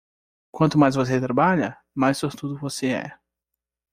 Read more Adj Noun Frequency C1 Pronounced as (IPA) /soʁˈtu.du/ Etymology From sorte (“fate, luck”) + -udo.